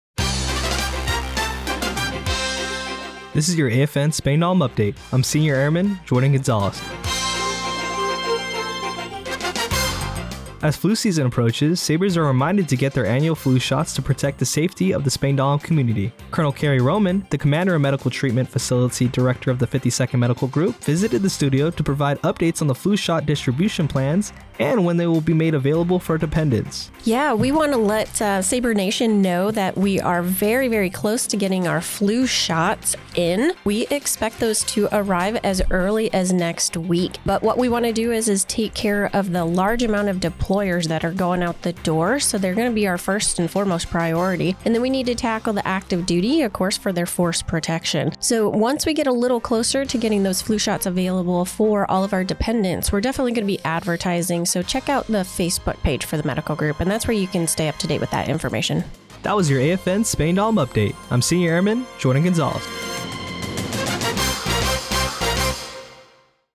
The following was the radio news report for AFN Spangdahlem for Oct. 23, 2024.